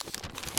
x_enchanting_scroll.2.ogg